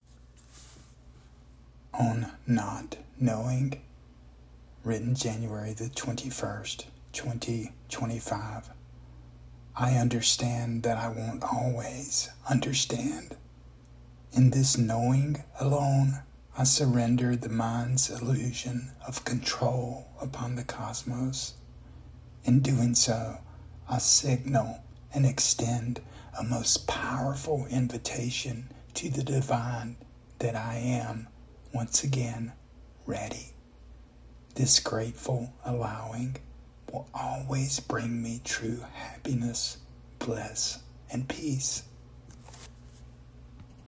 Spoken Audio